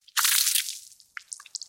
Звуки брызг крови
На этой странице представлена коллекция звуков брызг и капель крови в высоком качестве.
Кровь хлещет из вены